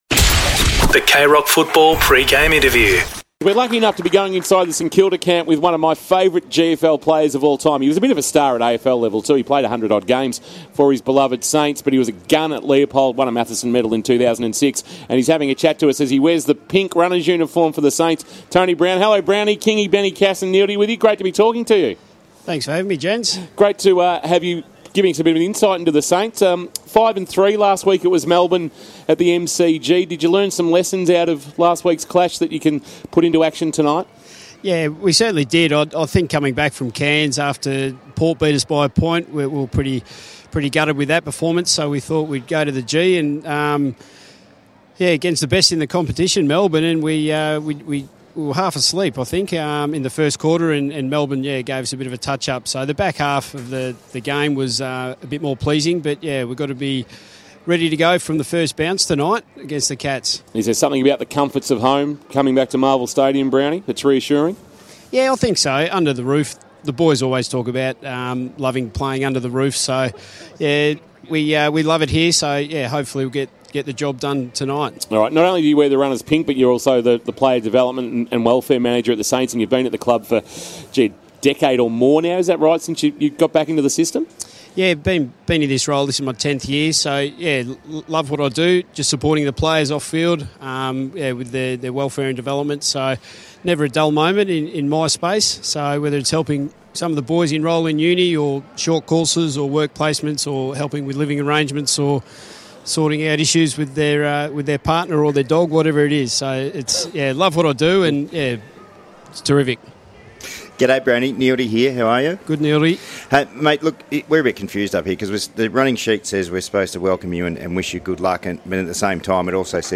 2022 - AFL ROUND 9 - ST KILDA vs. GEELONG: Pre-match Interview